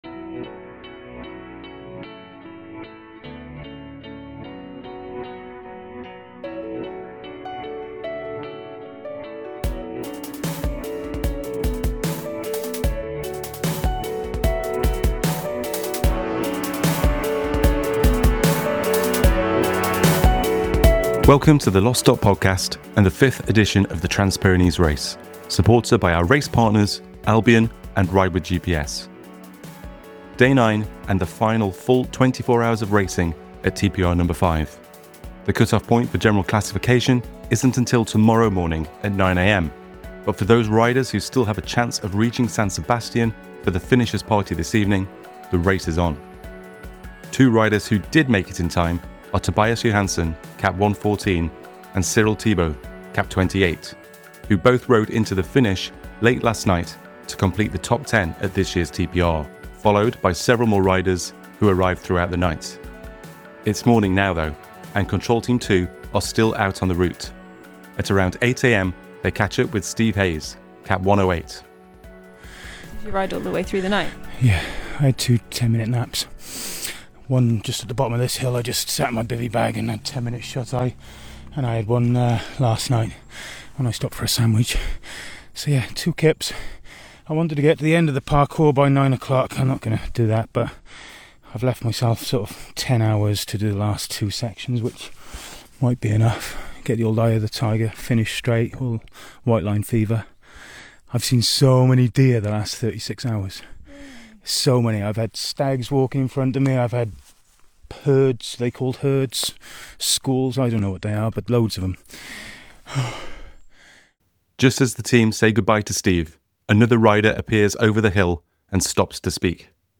TPRNo5 // Day 09 Oct 12, 2025, 08:02 PM Headliner Embed Embed code See more options Share Facebook X Subscribe It’s the day of the finisher’s party, and emotions are running high as riders reach the finish line. Our race reporters are there to capture every raw, unfiltered story. Step onto the streets of San Sebastián, and back along the Raid Parcours, to hear from riders as they close in on the end of their TPRNo5 journey.